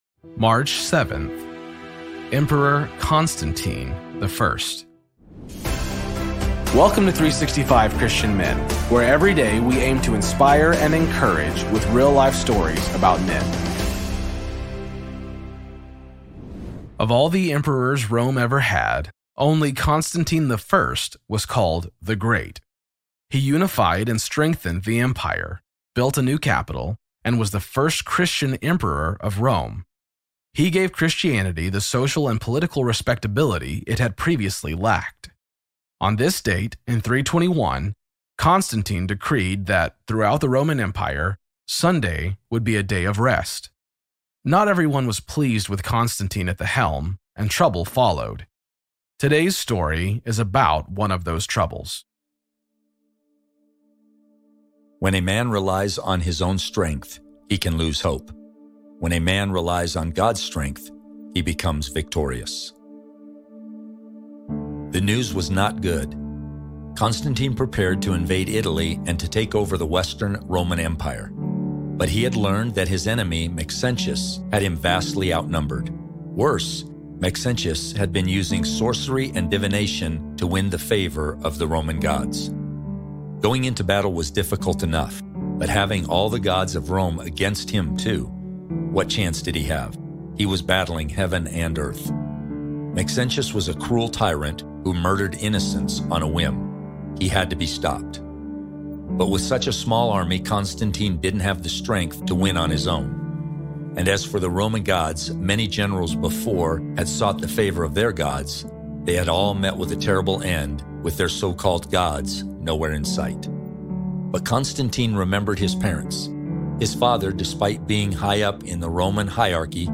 Story read